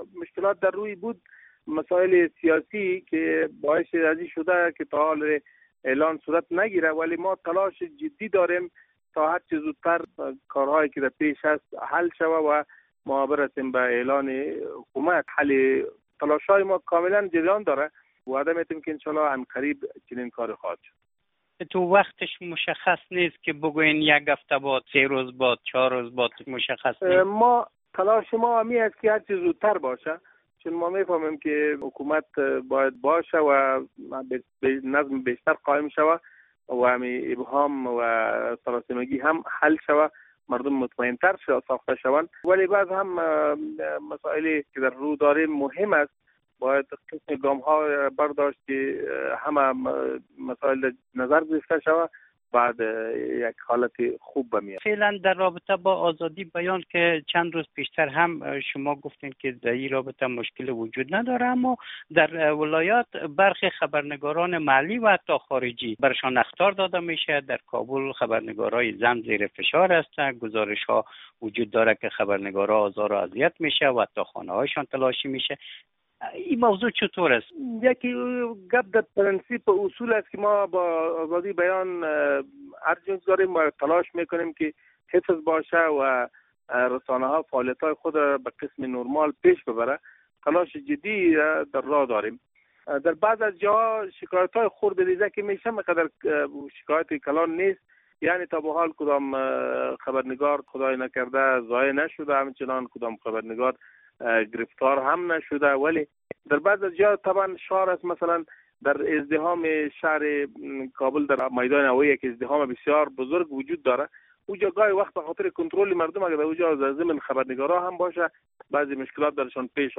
مصاحبه - صدا
ذبیح‌الله مجاهد، سخنگوی طالبان